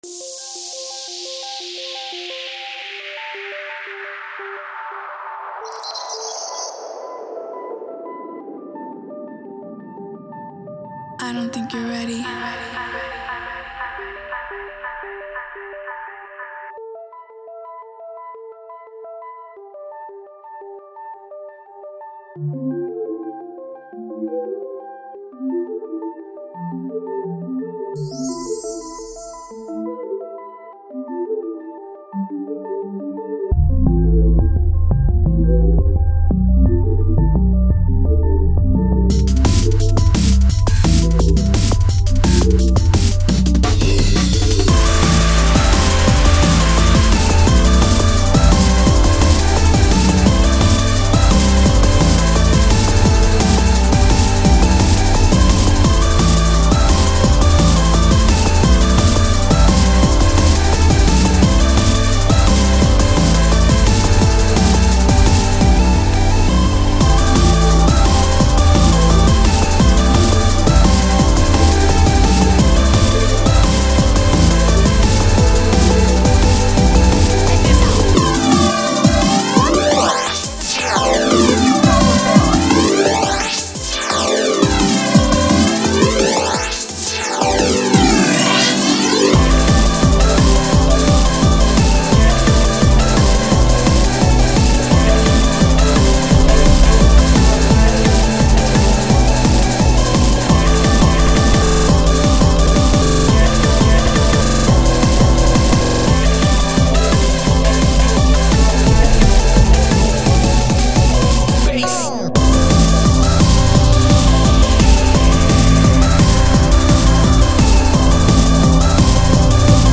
BGM
EDMインストゥルメンタルロング